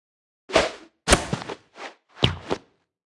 Media:Sfx_Anim_Ultra_Bo.wav 动作音效 anim 在广场点击初级、经典、高手和顶尖形态或者查看其技能时触发动作的音效
Sfx_Anim_Baby_Bo.wav